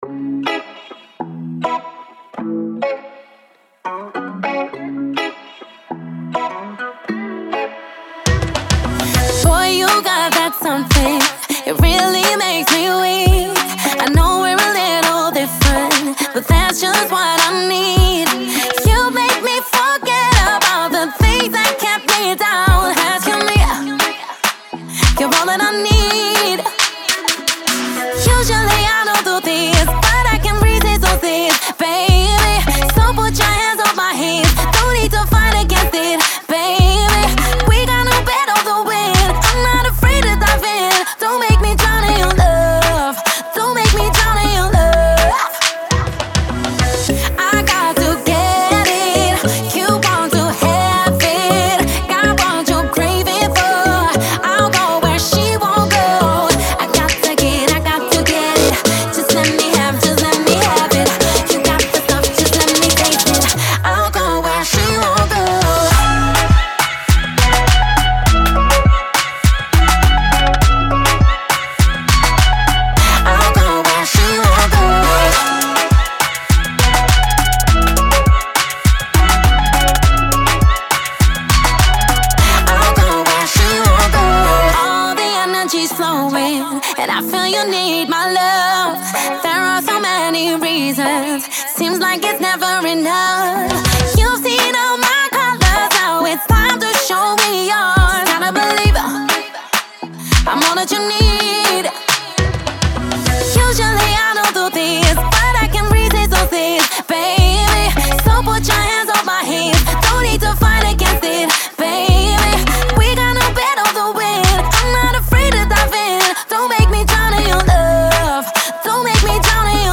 электронная поп-композиция